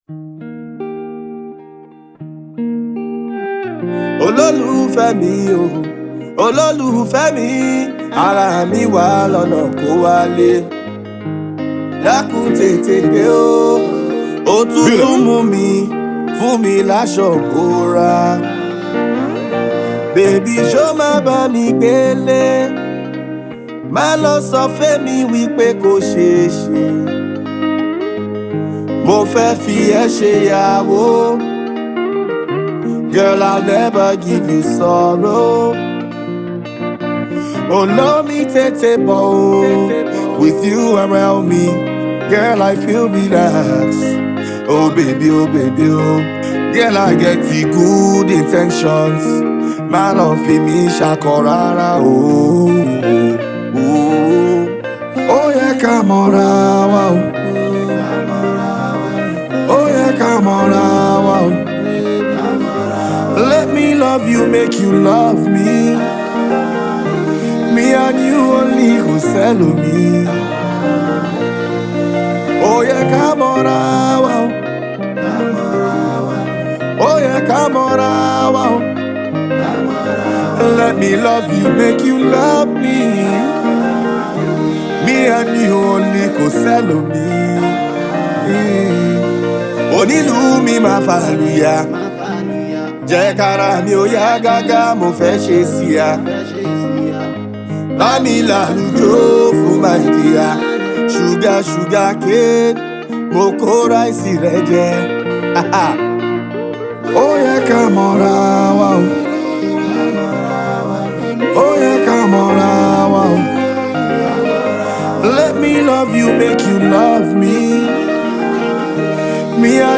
Nigerian rapper and singer